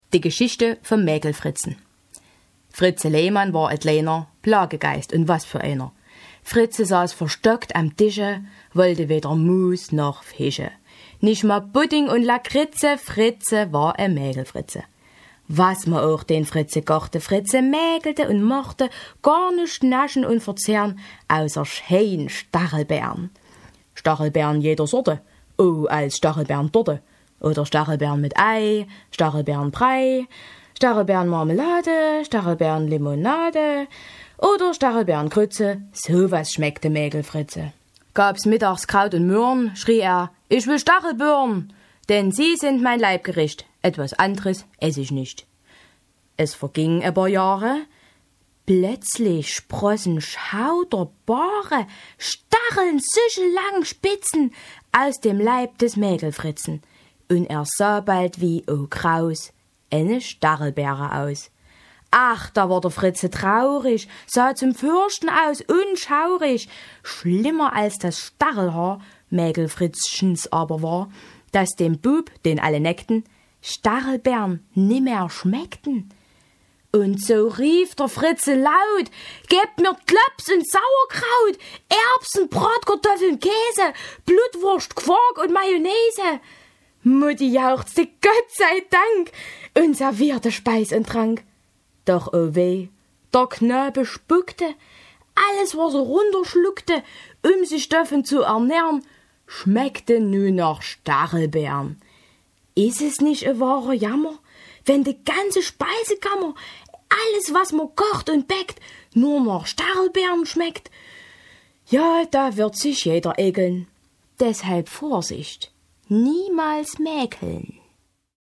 Fröhliche Weihnachten uff sächs`sch